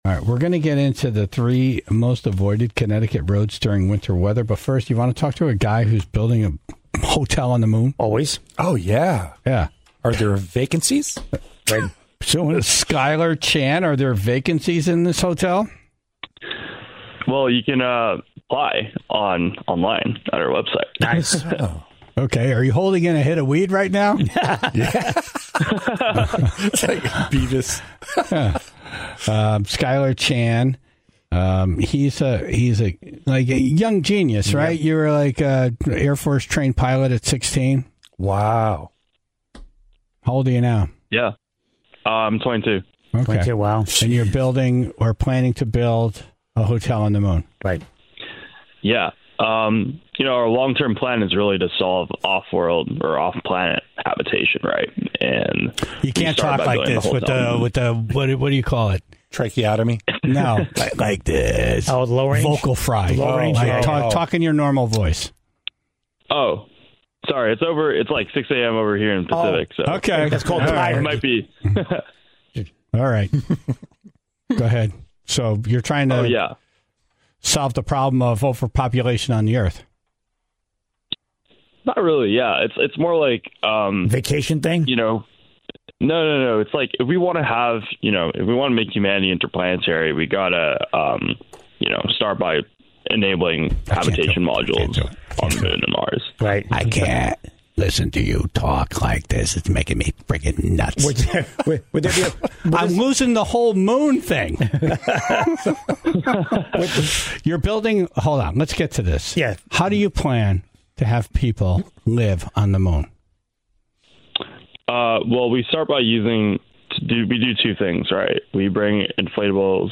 The interview was cut short when the vocal fry was too much to handle.